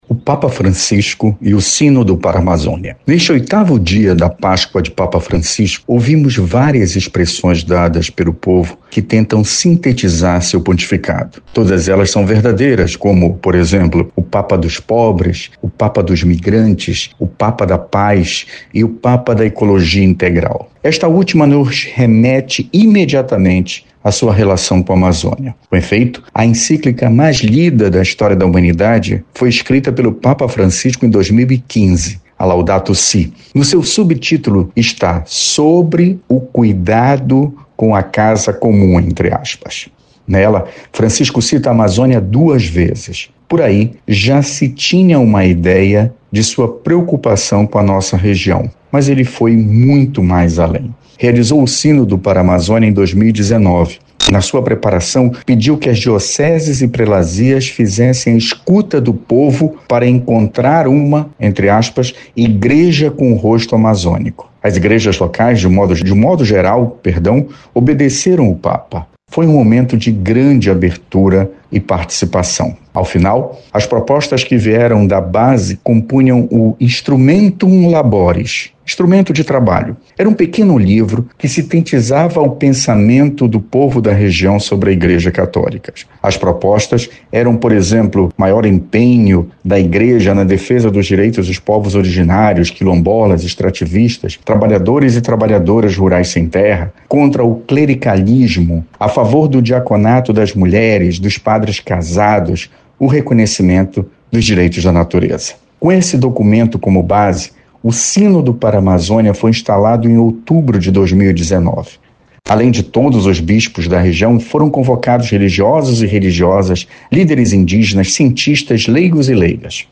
Confira o editorial de Felício Pontes, Procurador Regional da República. Na ocasião, ele relembra a liderança do Papa no Sínodo e a defesa dos povos e da natureza.